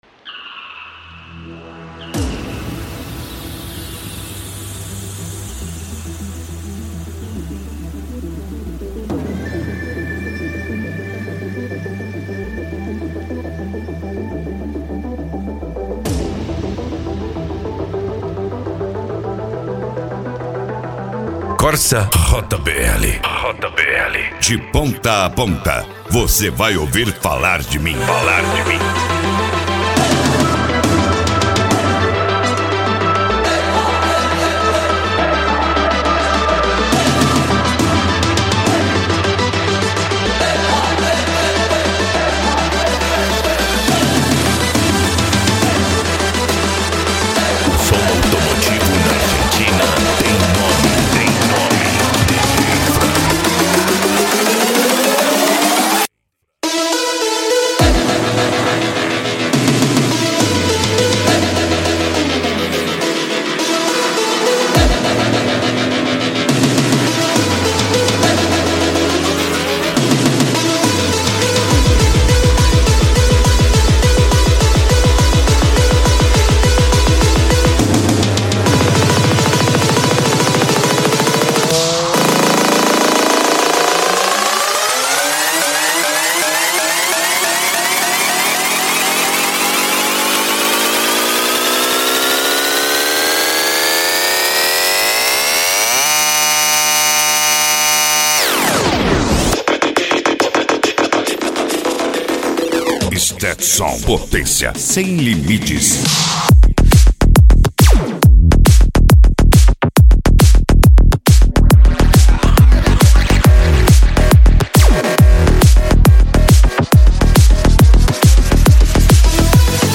Eletronica
PANCADÃO
Psy Trance